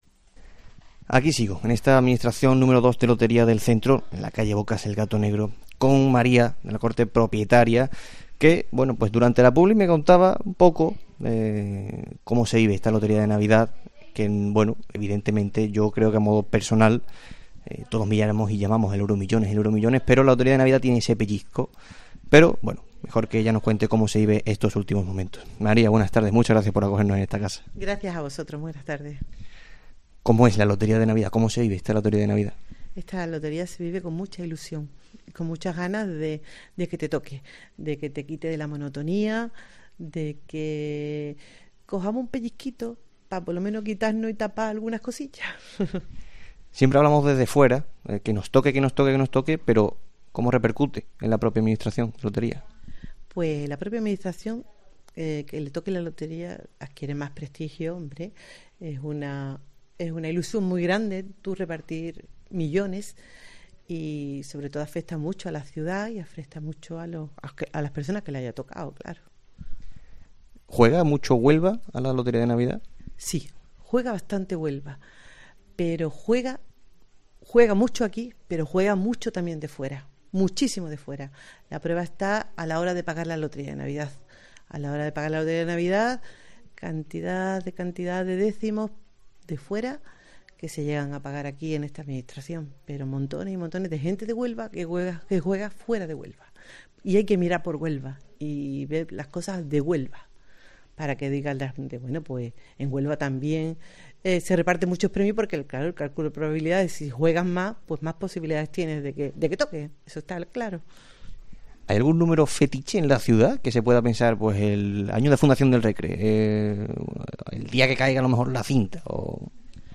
AUDIO: Desde la Administración de Lotería nº2, 'El Gato Negro', realizamos este tiempo local de Herrera en COPE para analizar la Lotería de Navidad...